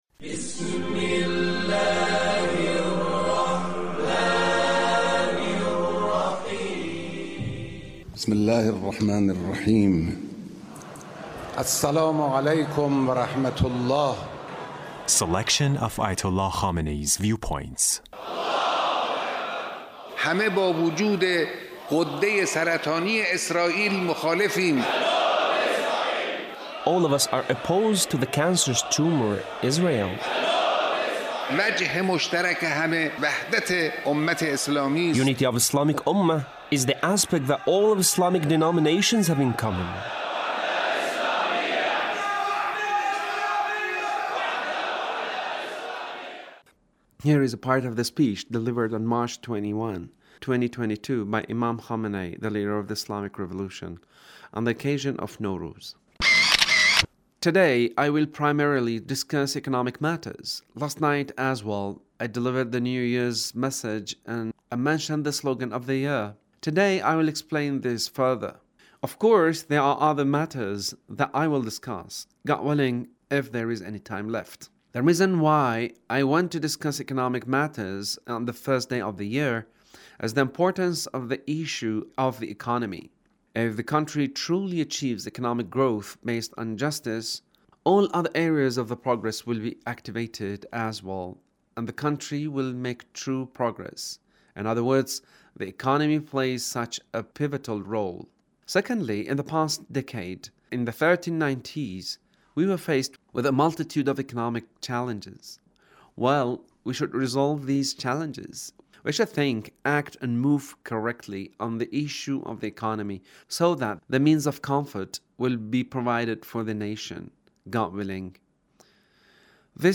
Leader's Speech (1662)
Leader's Speech on The Month of Ramadhan